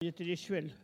Enquête Arexcpo en Vendée
Collectif-Patois (atlas linguistique n°52)